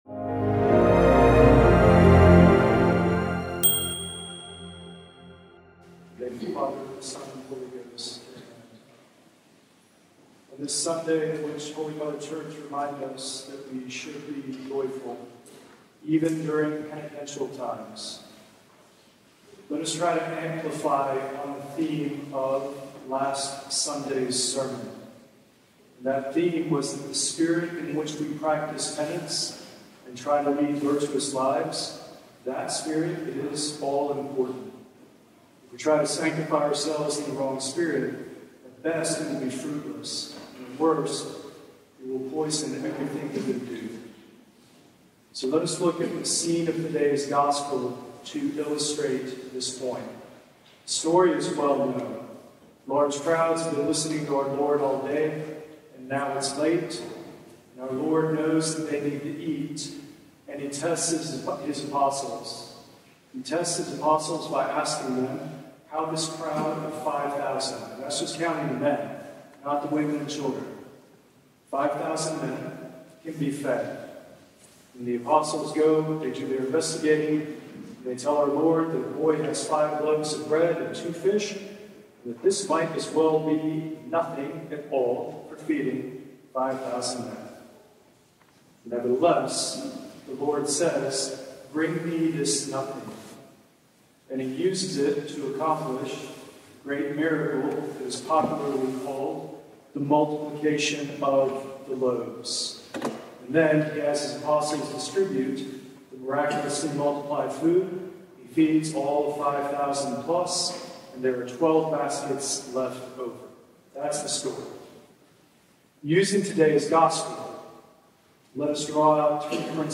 Sermon-69-Audio-converted.mp3